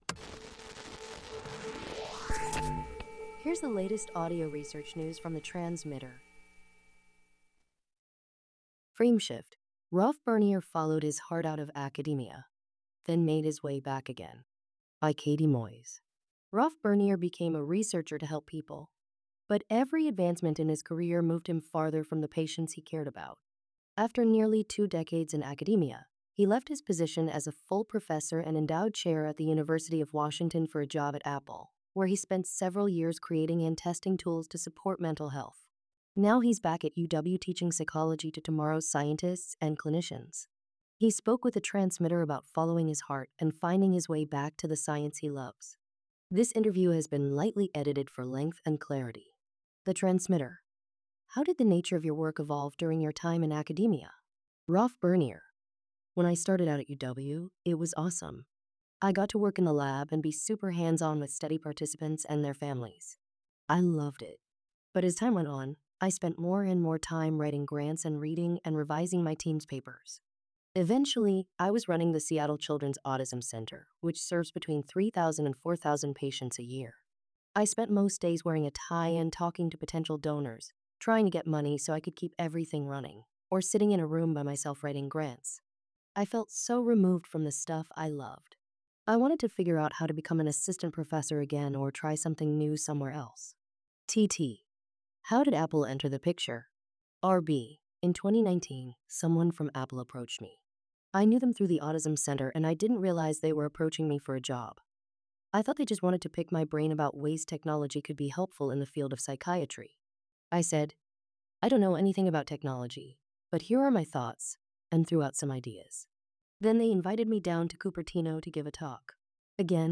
This interview has been lightly edited for length and clarity.